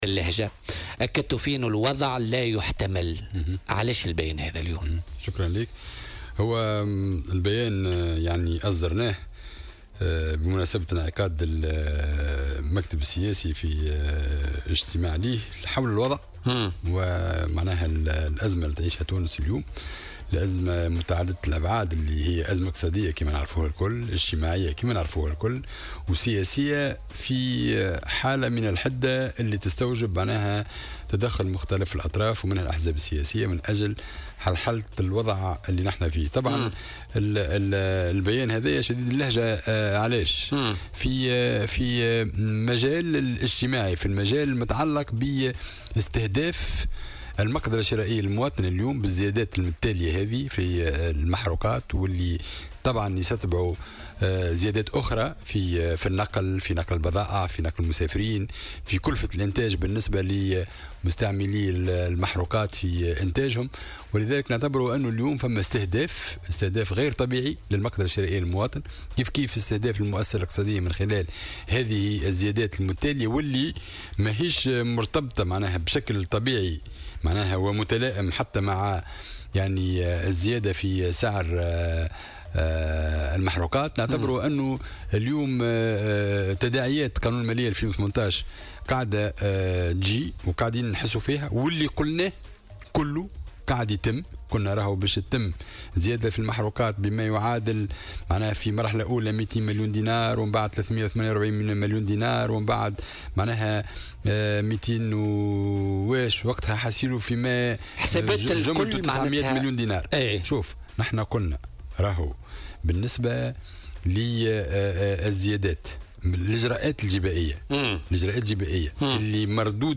وقال ضيف "بوليتيكا" على "الجوهرة اف أم" إن هناك استهدافا للمقدرة الشرائية للمواطن بعد الزيادة الأخيرة للمحروقات واصفا الأمر بـ "غير الطبيعي".